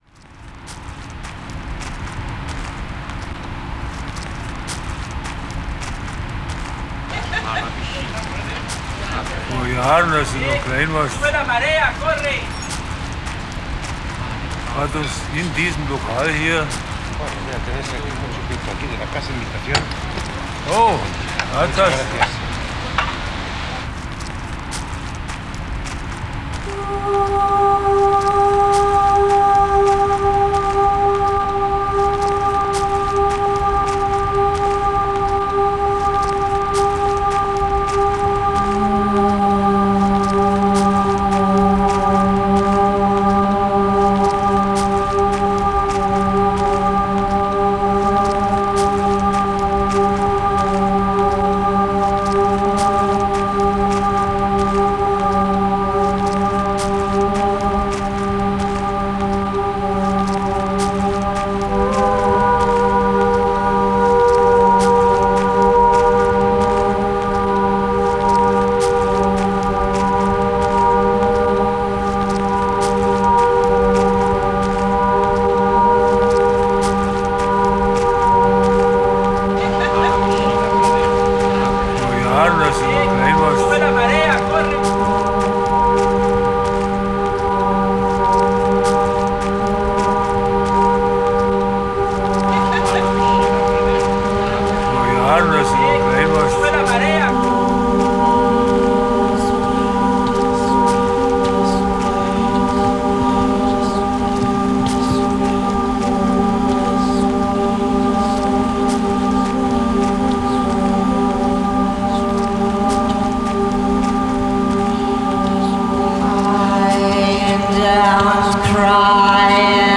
Die Arbeit YEARS wird eine malerisch-akustische Intervention vor Ort sein. Hier werden Live-Sounds generiert und im Zusammenhang dieser wird ein Wandbild entstehen, dass sich mit dem Werksviertel auseinandersetzen wird.